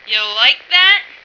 flak_m/sounds/female1/int/F1likethat.ogg at fd5b31b2b29cdd8950cf78f0e8ab036fb75330ca